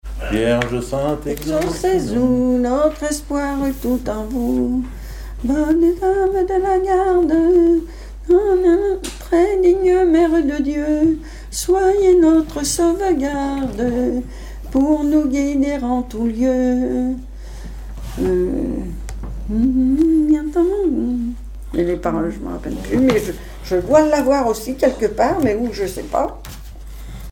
circonstance : cantique
Genre strophique
Catégorie Pièce musicale inédite